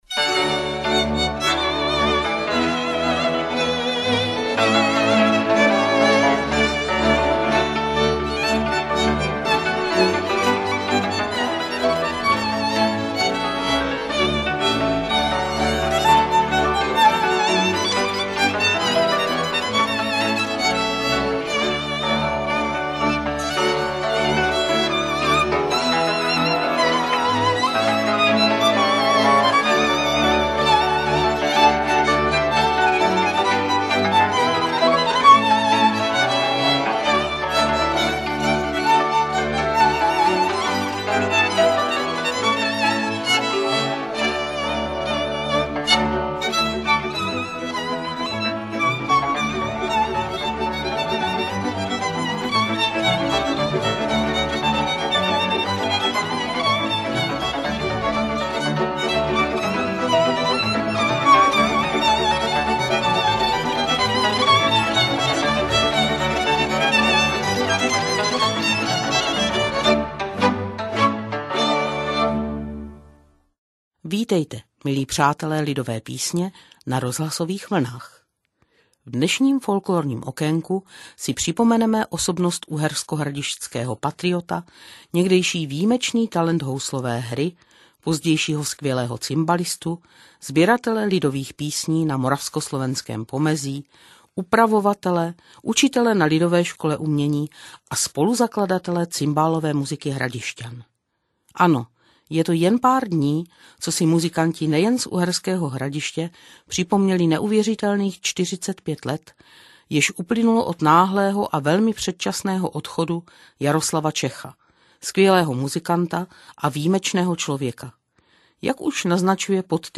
Listí stromů bylo odedávna vnímáno jako symbol koloběhu života v přírodě i fází lidského života; jejich prostřednictvím lidová poetika vyjadřovala zrození, stárnutí i smrt, byly zástupným symbolem pro obnovu života i sil. Lidových písní o listech keřů i stromů je mnoho, nabídneme skromný vzorek.